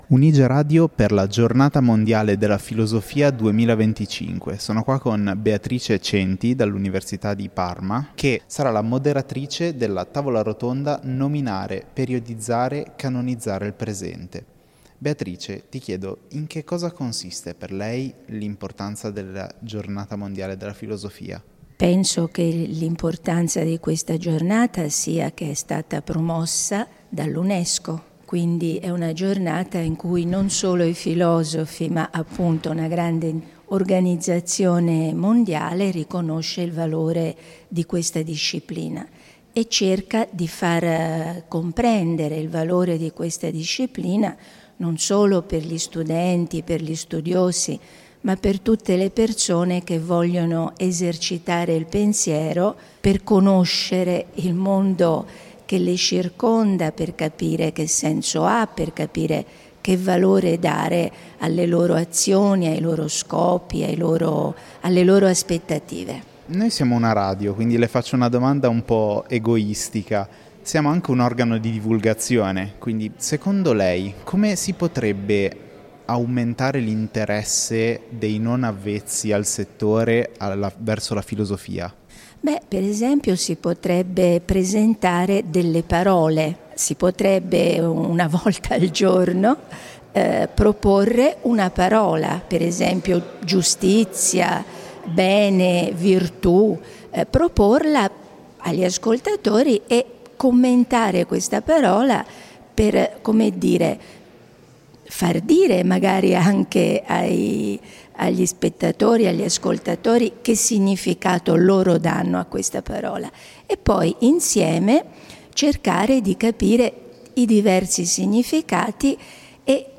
Un dialogo sulla filosofia come pratica di condivisione, linguaggio e pensiero comune.